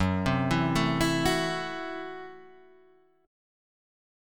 Gbm7b5 Chord